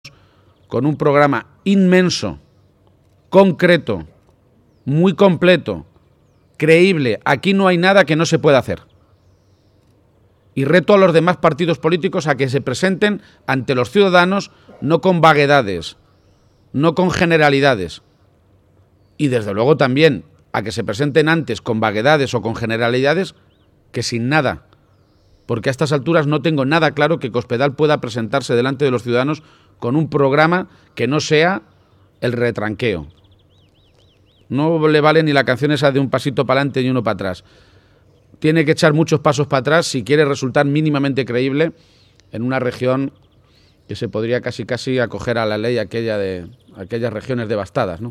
Audio Page-presentación programa electoral 2